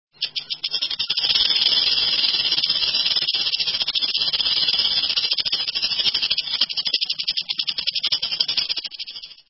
rattlesnake
ratlsnk.mp3